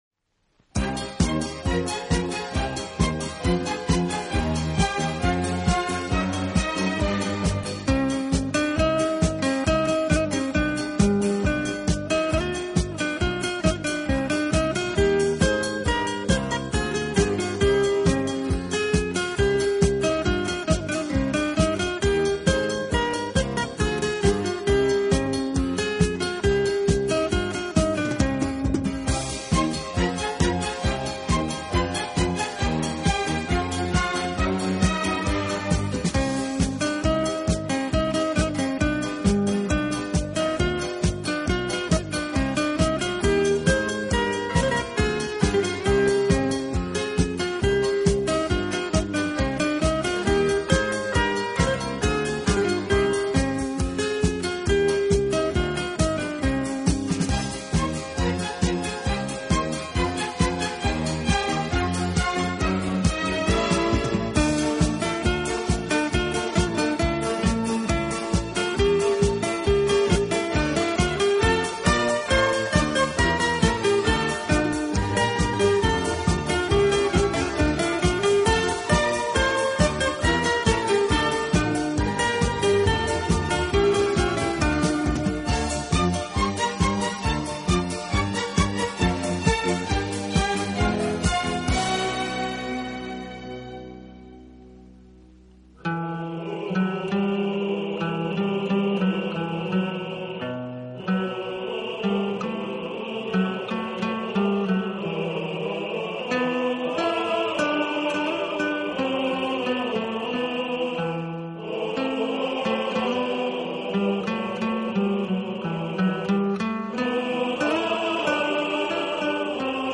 Quality: HQ VBR Mp3 / 48Khz / Joint-Stereo